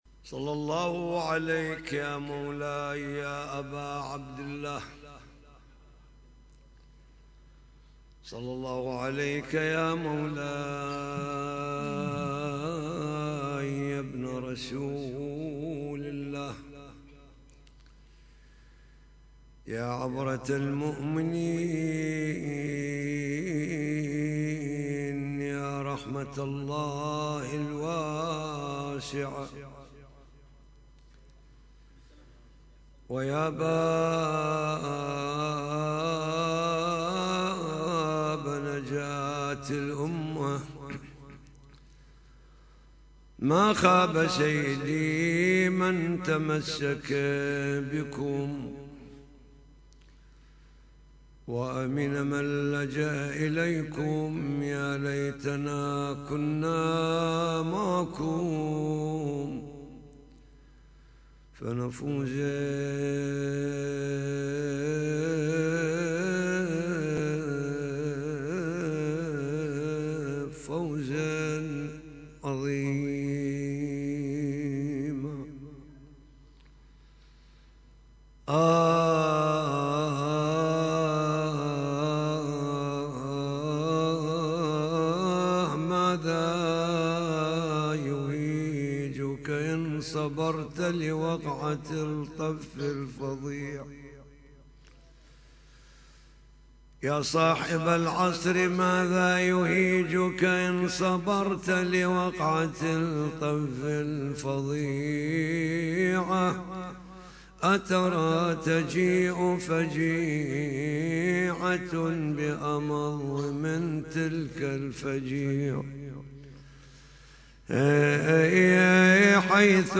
محاضرة ليلة 28 جمادى الأولى